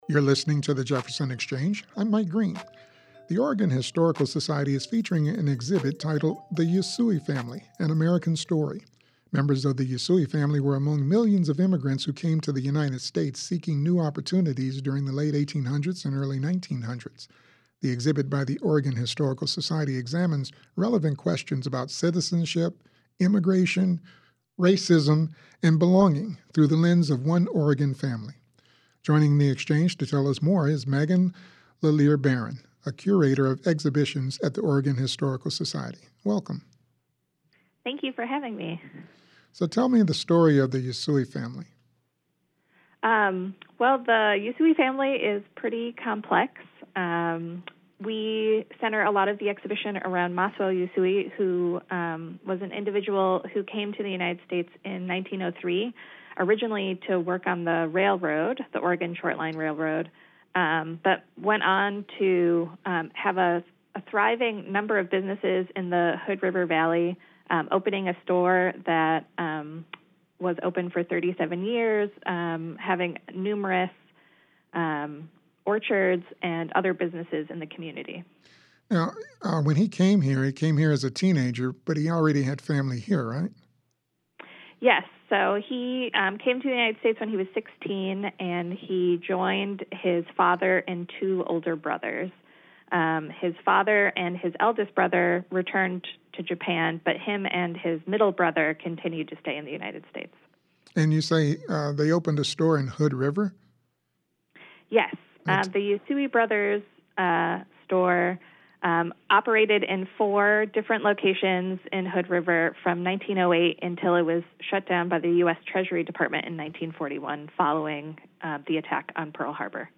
Play Rate Listened List Bookmark Get this podcast via API From The Podcast 1 JPR's live interactive program devoted to current events and newsmakers from around the region and beyond.